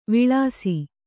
♪ viḷāsi